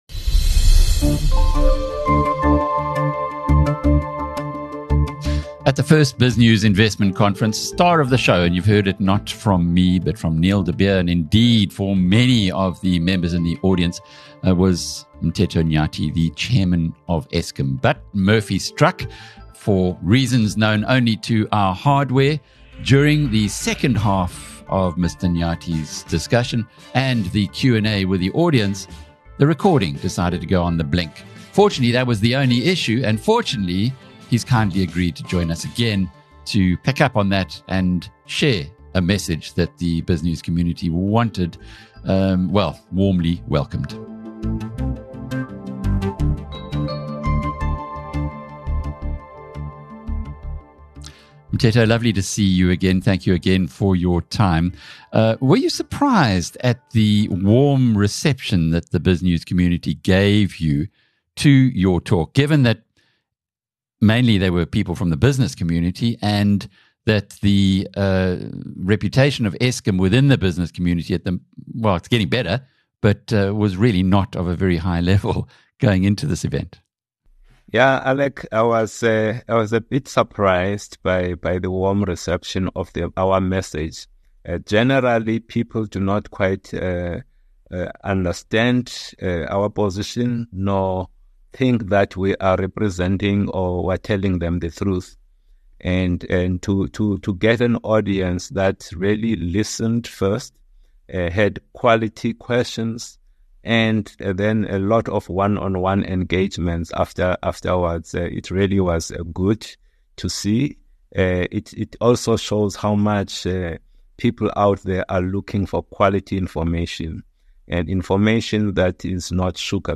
The interview sheds light on how Eskom is tackling long-standing issues by leveraging data analytics and innovative solutions, aiming to ensure energy stability while addressing corruption within the company.